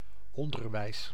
Ääntäminen
IPA: [ɑ̃.sɛ.ɲə.mɑ̃]